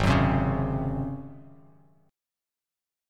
G#11 chord